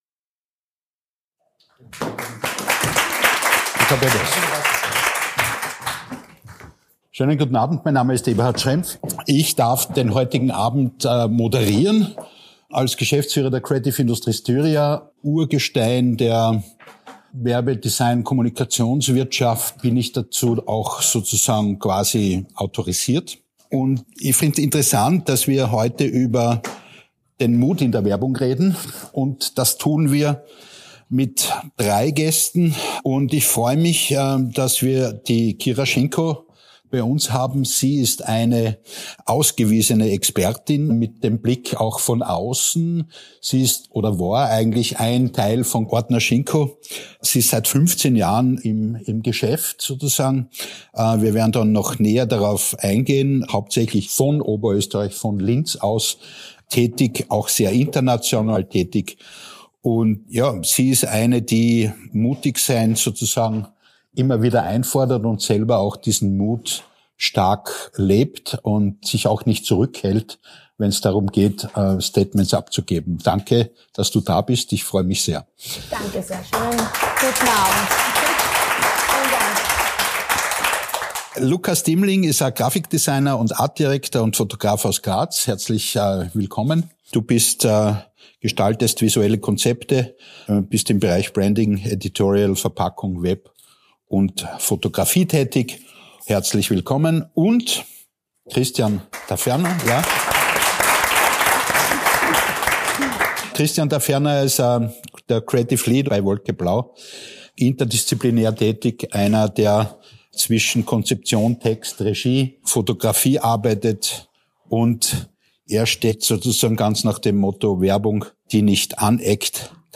live vor Publikum über Mut in der Werbung. Gemeinsam stellen sie sich die Frage, ob Werbung nur verkaufen oder auch verändern muss.